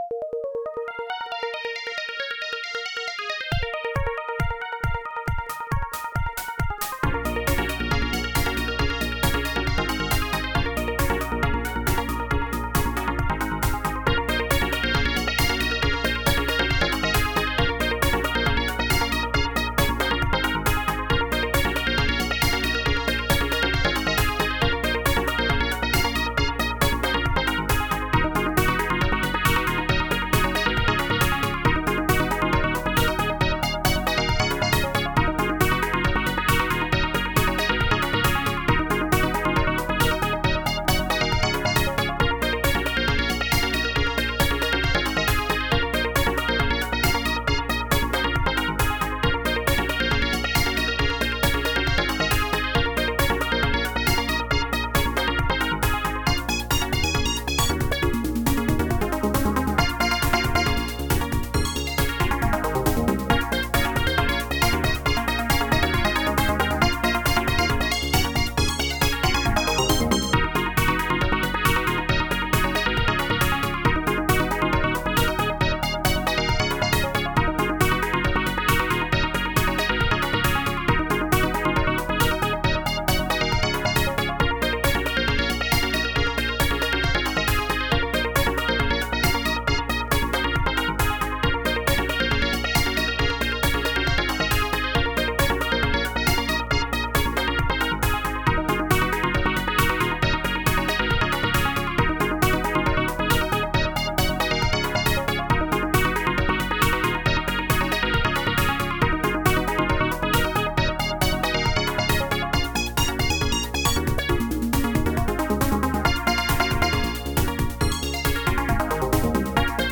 HSC AdLib Composer
jazzpop.mp3